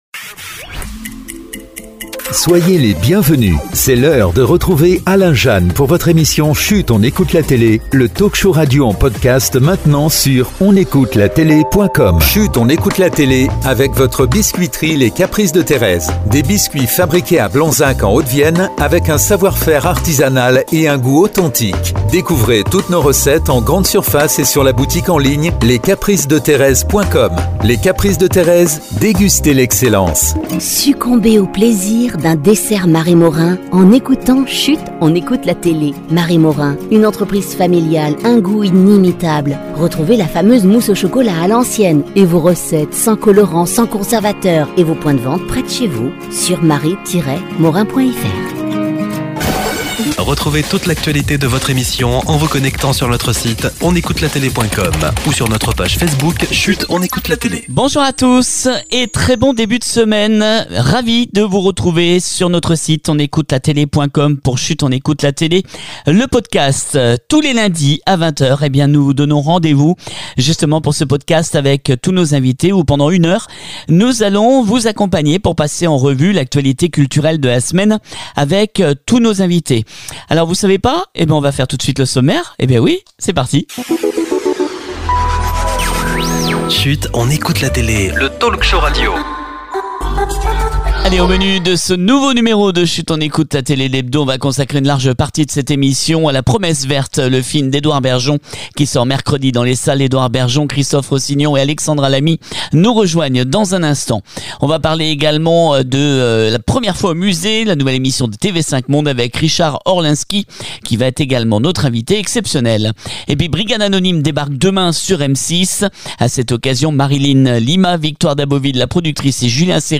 On se retrouve ce lundi 25 mars avec une émission événement consacré en partie à la sortie du film “la promesse verte” avec le producteur Christophe Rossignon, le réalisateur Edouard Bergeon et Alexandra Lamy qui sont avec nous
Richard Orlinski est également notre invité exceptionnel pour la première fois dans l’émission pour nous parler de son émission qui débarque mercredi 27 mars sur TV5 monde “première fois au musée”